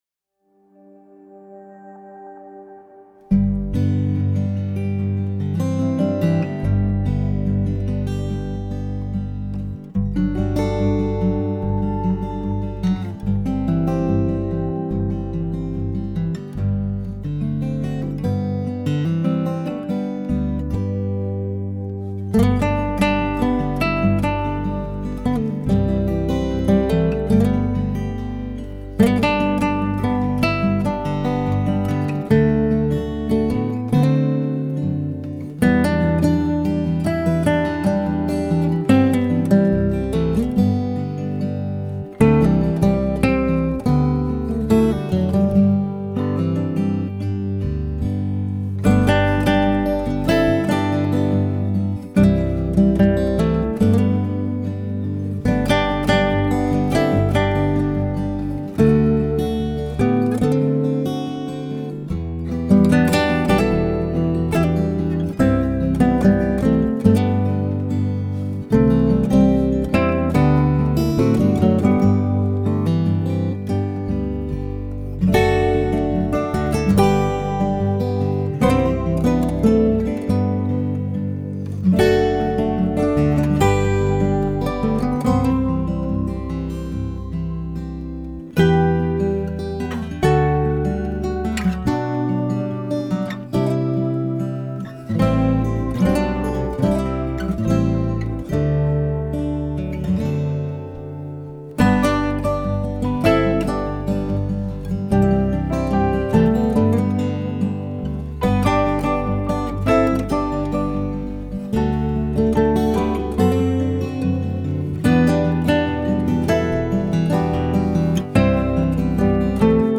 special instrumental recording